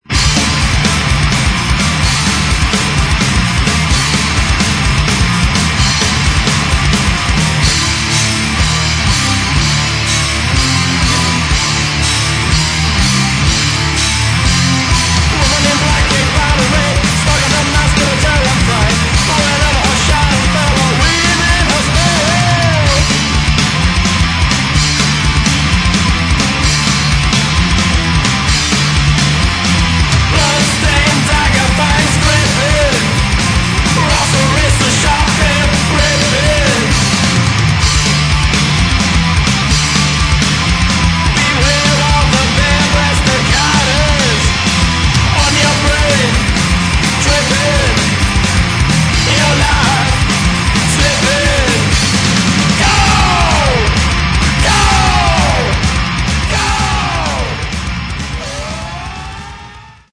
Metal
Очень тяжелая и жесткая музыка, с хорошим вокалом.